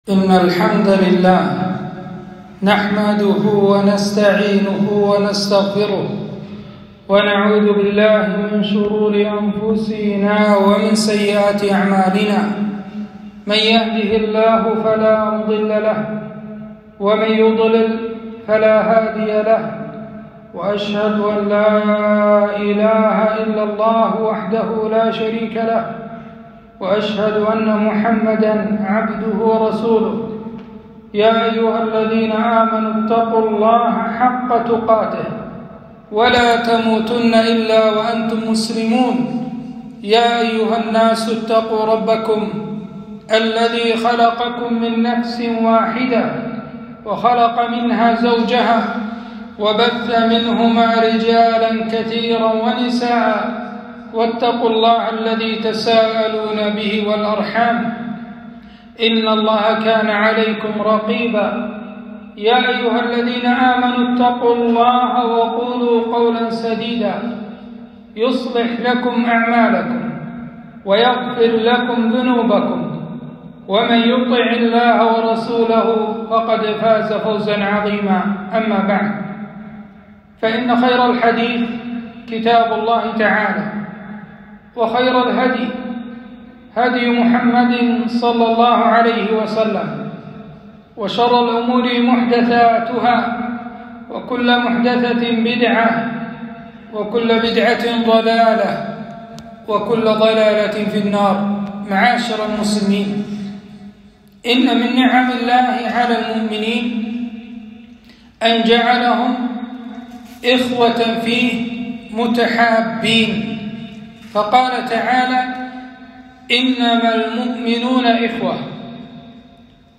خطبة - إنما المؤمنون إخوة